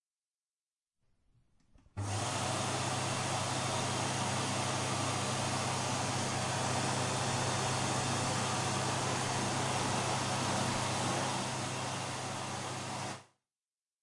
描述：这个声音是在Pompeu Fabra大学（巴塞罗那）Campus de la Comunicació的Taller's building bathroom里用Zoom H2录音机录制的。 我们所感受到的是风从干手机中吹出的声音。
Tag: 校园UPF 烘干机 UPF-CS13